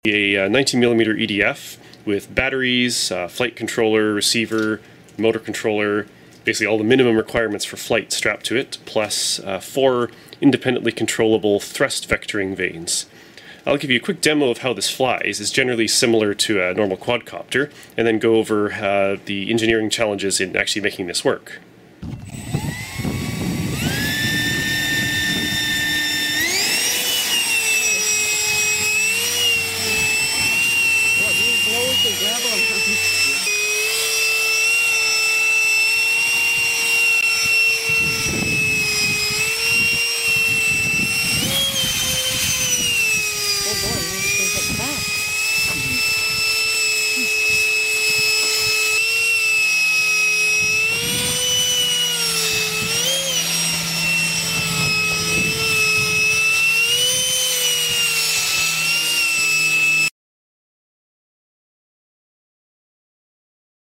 90mm EDF Electric ducted fan sound effects free download
Tesla rocket thrust-vectored flying duct fan